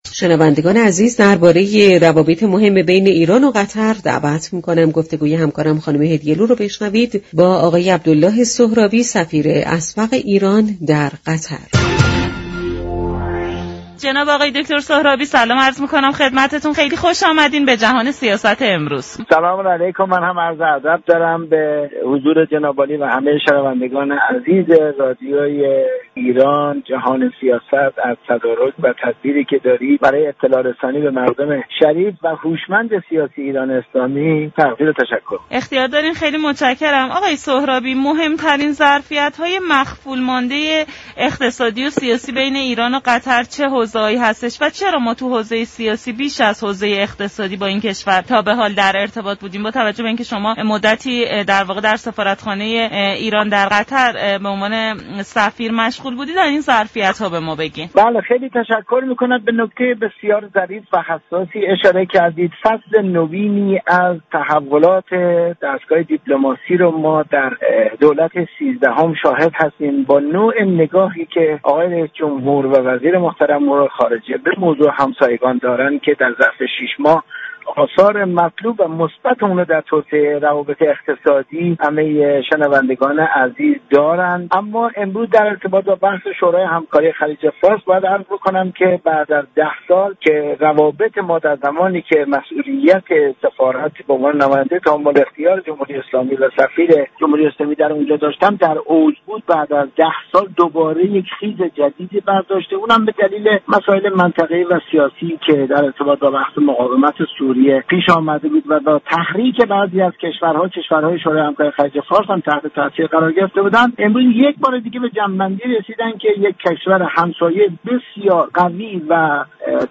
به گزارش شبكه رادیویی ایران، عبدالله سهرابی سفیر اسبق ایران در قطر در برنامه «جهان سیاست» به بحث درباره روابط ایران با كشورهای همسایه پرداخت و گفت: با توجه به نگاه رییس جمهور و وزیر امور خارجه به كشورهای همسایه، امروز در دستگاه دیپلماسی ایران شاهد فصل نوینی از تحولات هستیم.